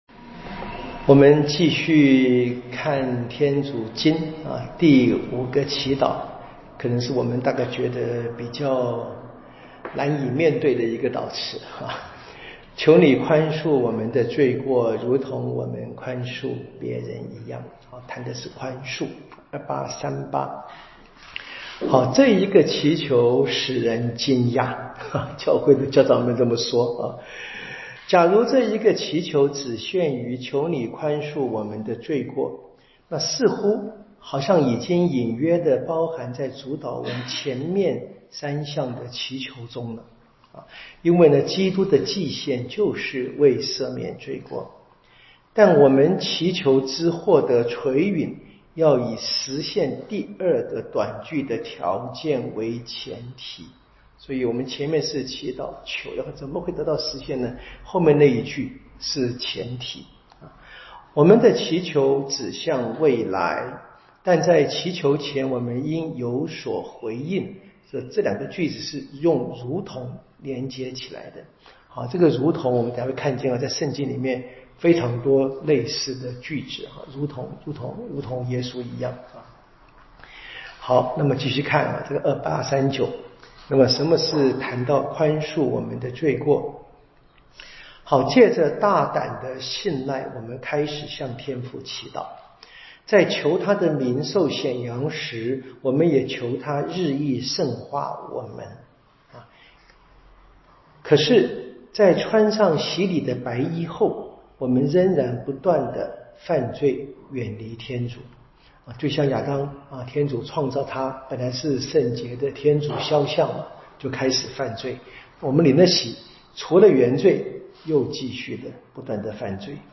彌撒講道與聖經課程